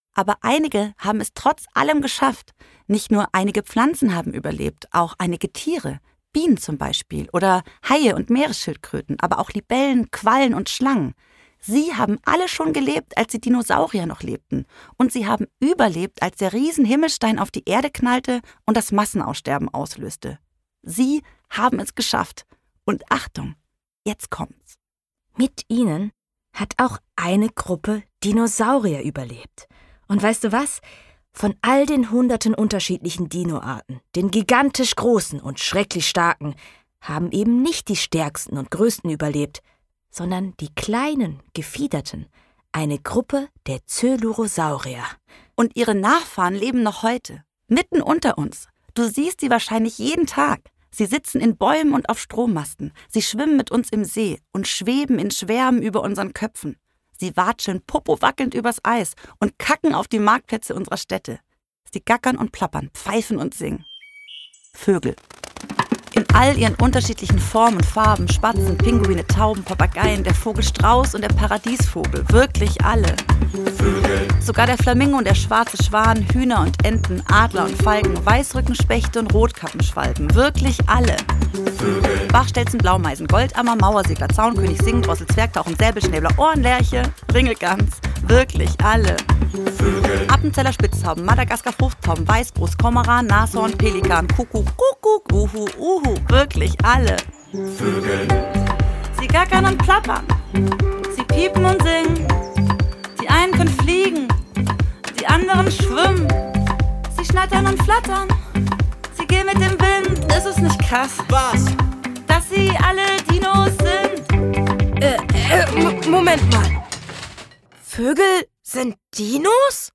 Hörbuch: BiBiBiber hat da mal 'ne Frage.